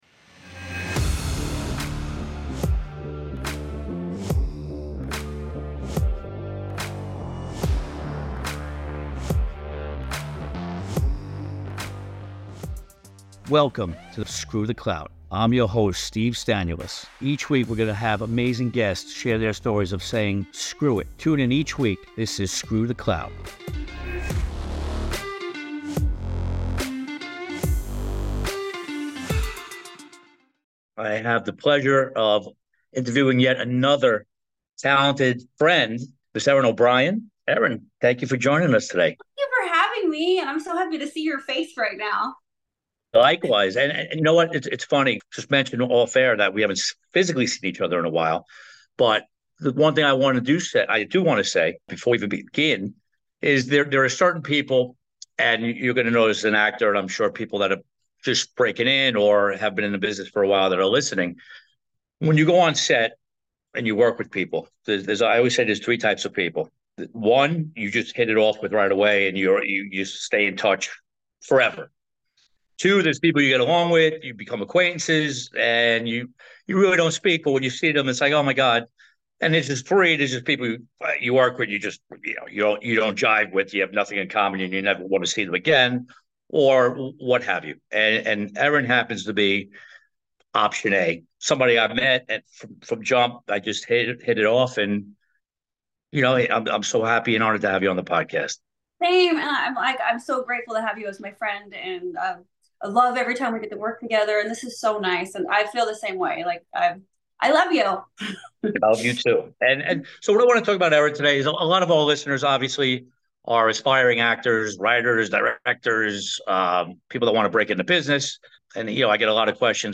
Personal Stories